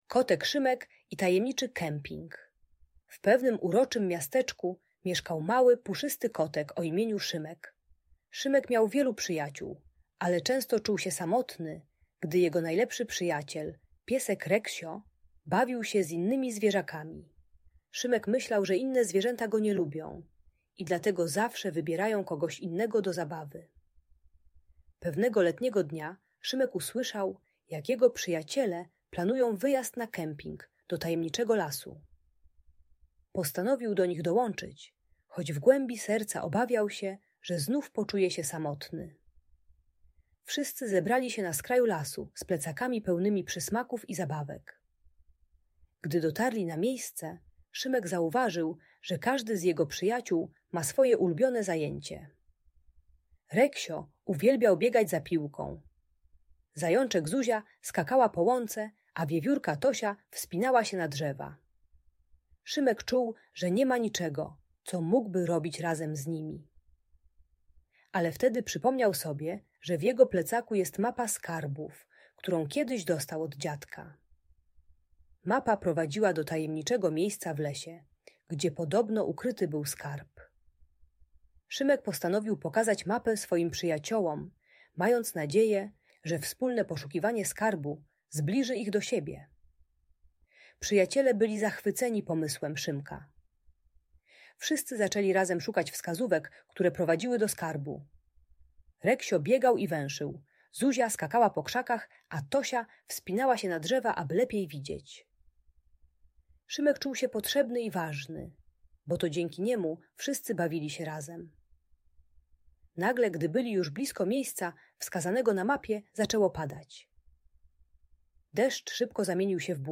Kotek Szymek i Tajemniczy Kemping - Audiobajka dla dzieci